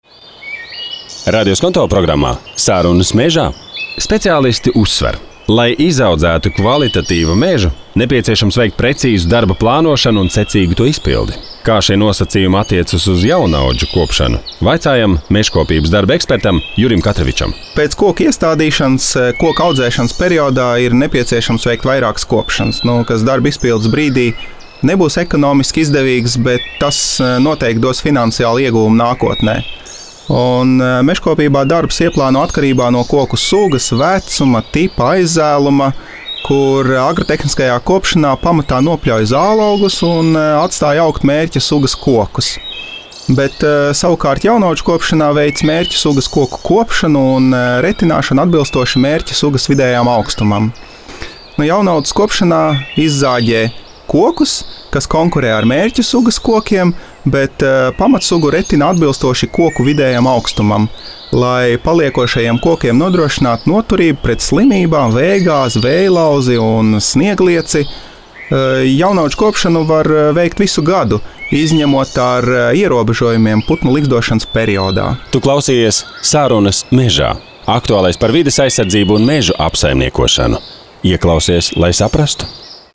Informācija, sarunas ar ekspertiem, neparasti fakti – tas viss “Sarunas mežā “.